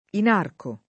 inarcare v.; inarco [ in # rko ], ‑chi